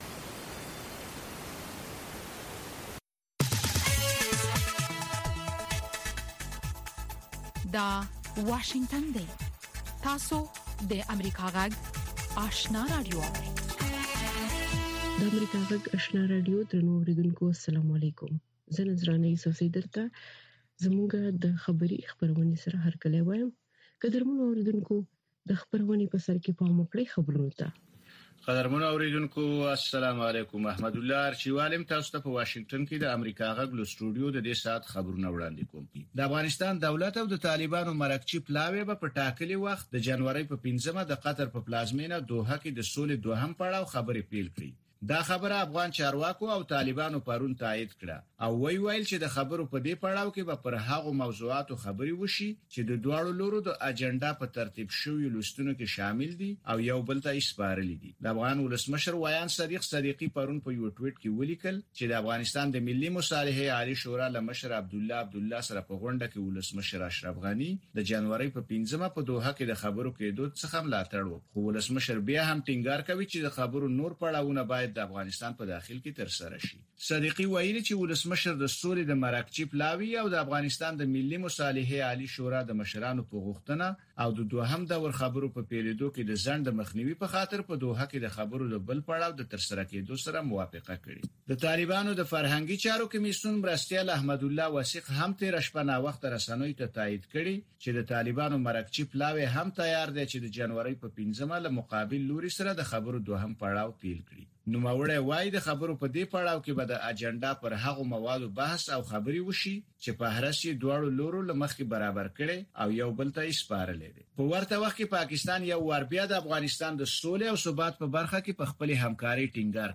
لومړۍ ماښامنۍ خبري خپرونه